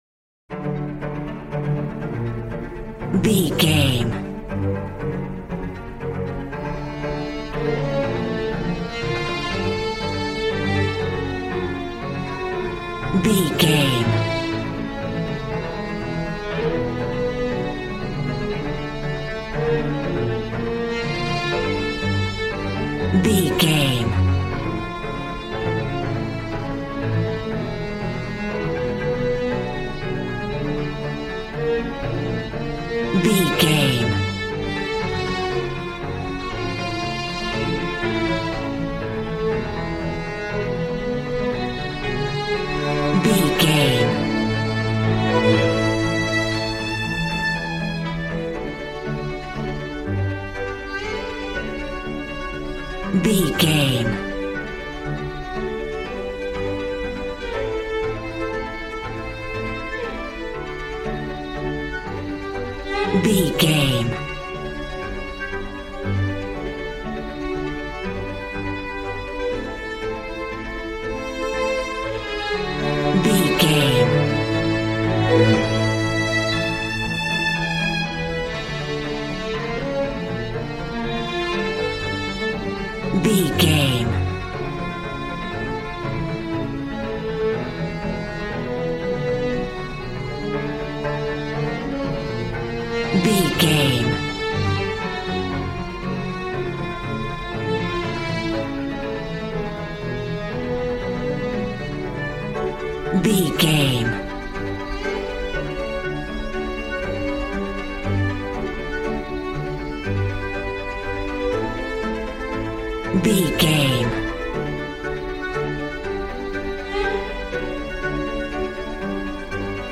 Ionian/Major
regal
cello
violin
brass